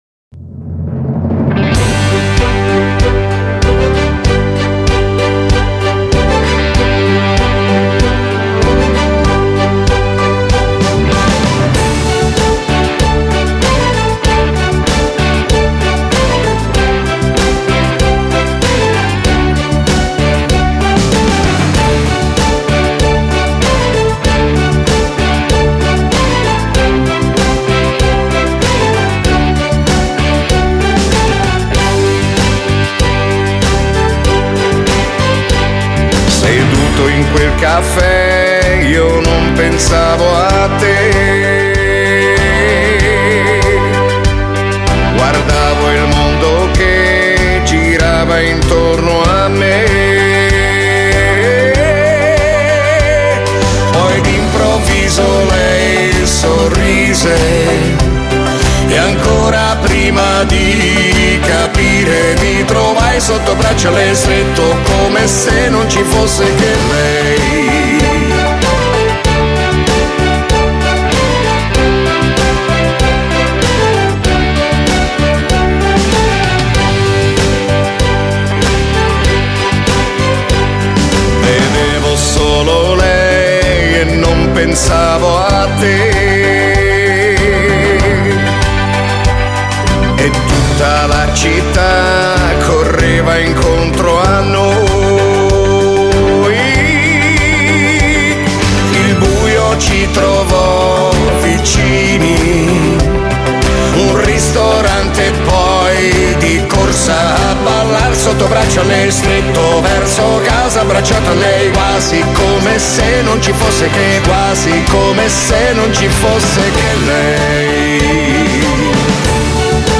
Genere: Cumbia